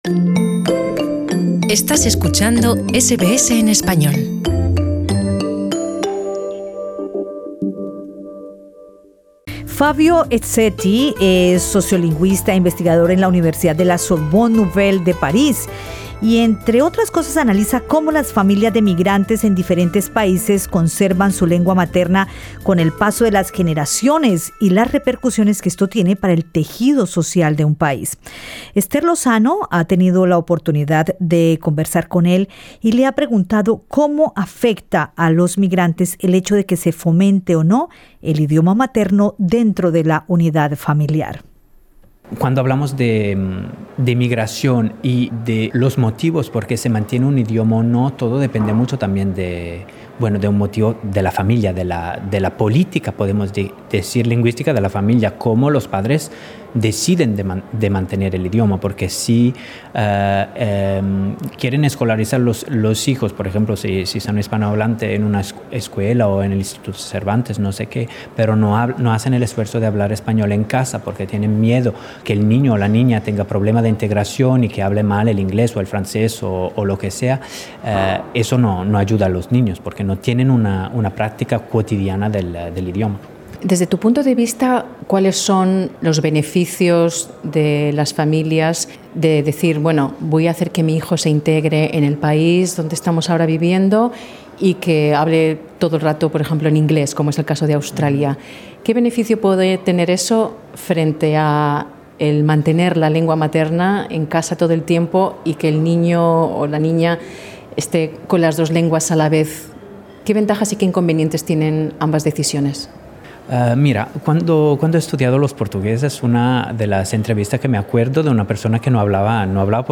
Escucha en nuestro podcast la entrevista completa